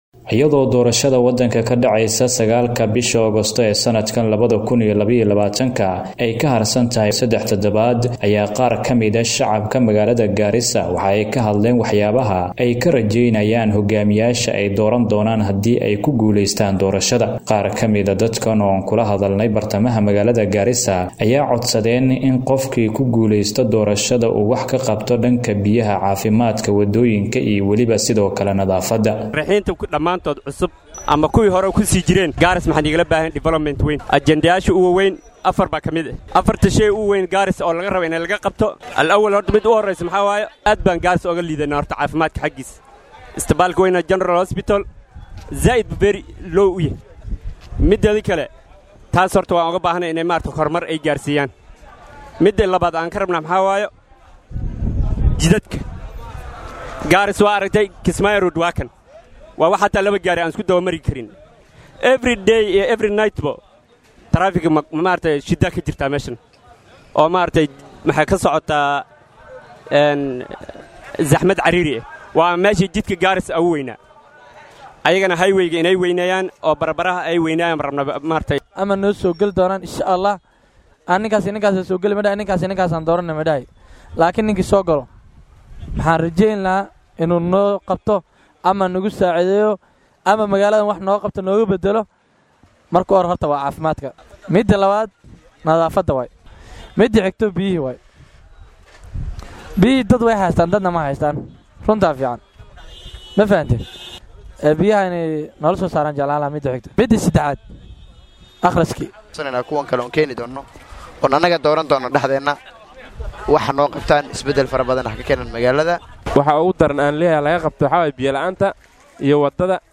Qaar ka mid ah shacabka magaalada Garissa ayaa baaq u diray musharraxiinta siyaasadeed iyagoo sidoo kale ka hadlay waxyaabaha ay ka rajeynayaan inay u qabtaan